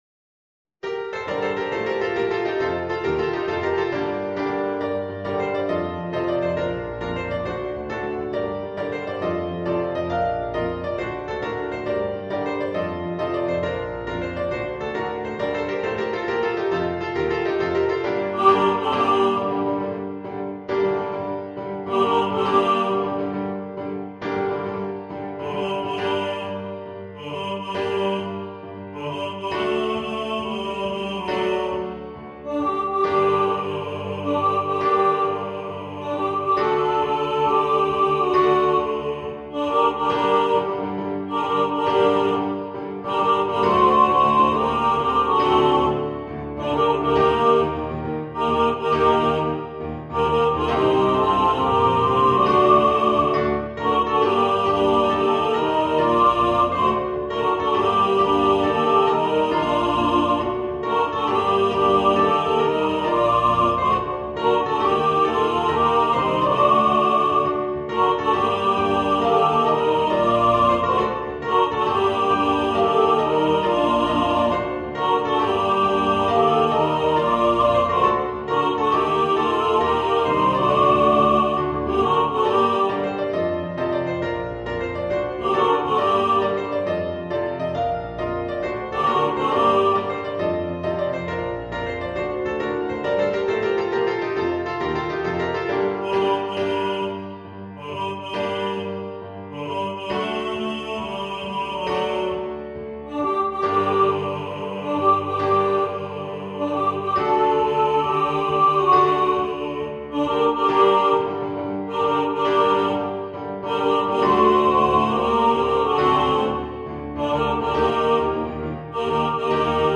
Harter Wind und Chormusik für raue Chöre